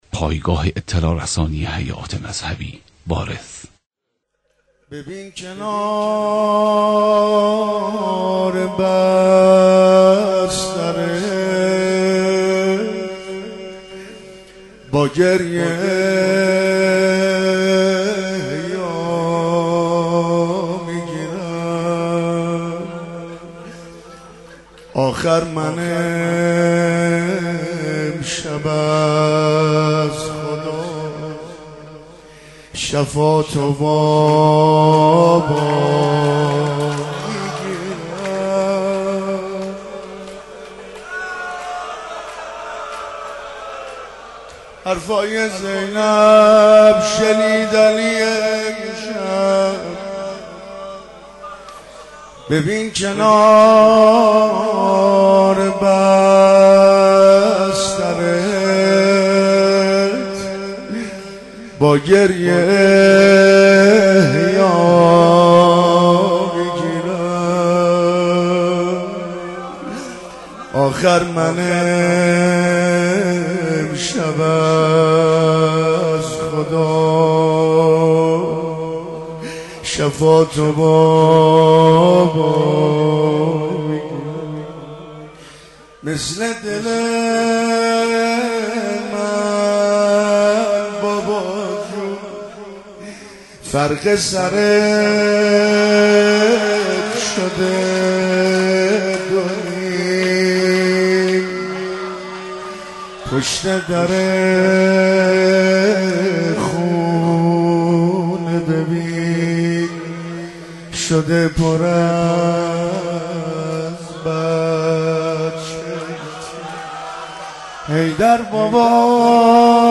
مناجات و روضه - مهدیه امام حسن